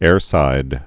(ârsīd)